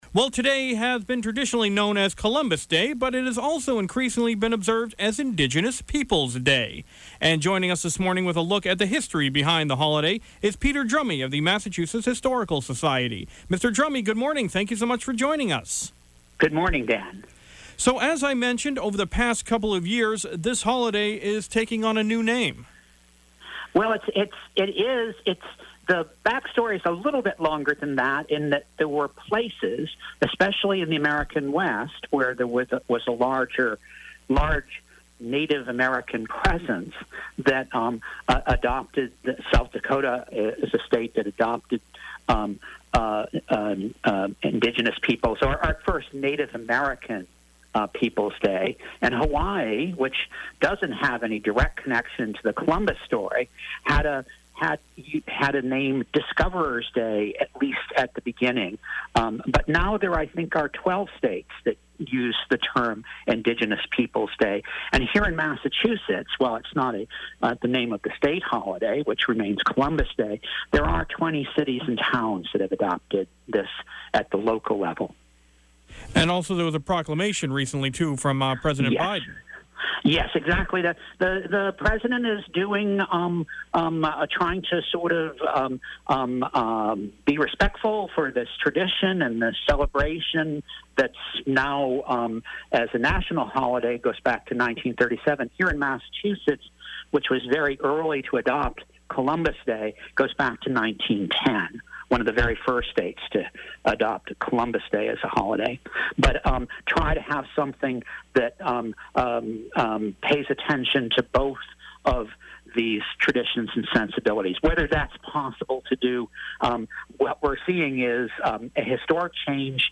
Historian Discusses Origins Of Columbus/Indigenous Peoples’ Day